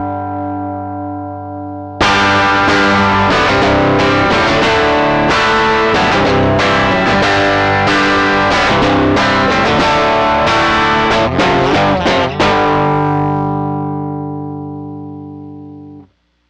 Not exactly what the amp would actually sound like but close, at least about what it would sound like into a dummy load with a simple 7K LP filter "speaker emulator" output.
The new 12AX7 model works better, less rounding on the bottom and simulated output sounds smoother.